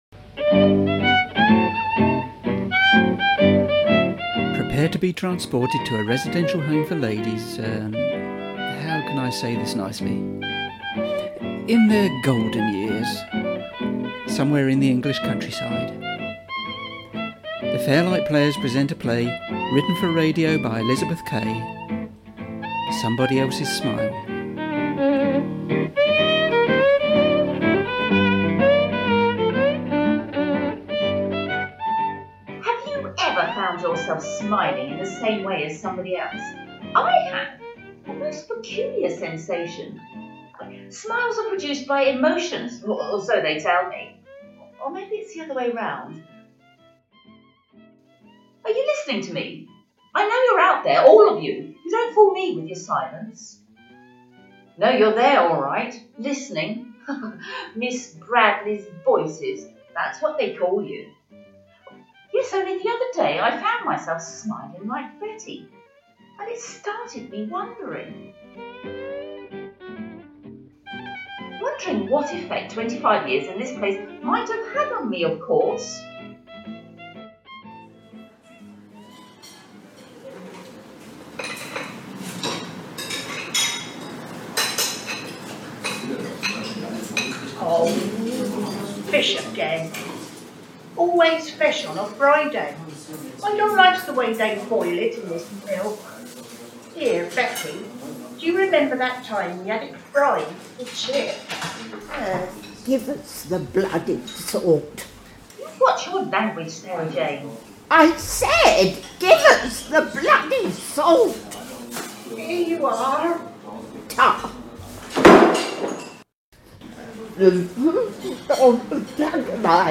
A radio play by the Fairlight Players